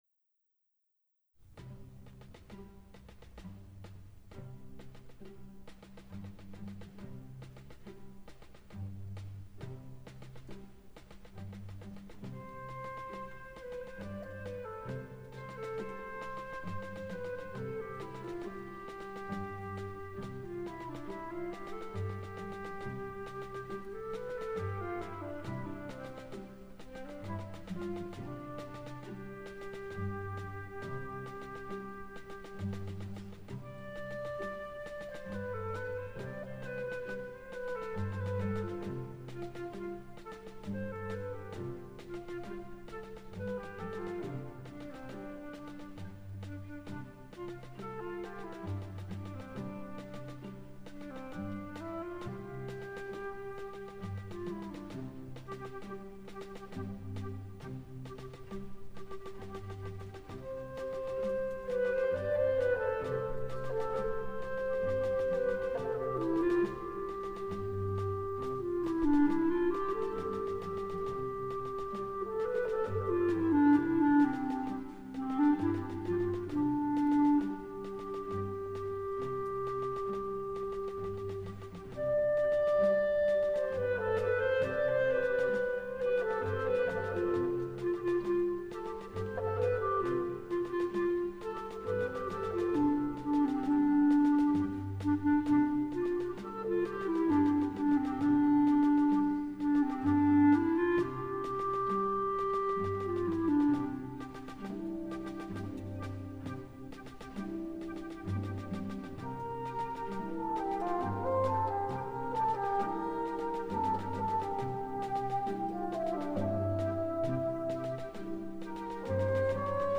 Думал, получится быстрее )) Итак, для начала анализ исходного Болеро в сравнении с компрессированным (для достижения -14 LUFS) файлом.
Прилагаю компрессированный файл для сравнения с исходником, чисто для ориентировки, поскольку, повторяю - алгоритмы стримов работают иначе.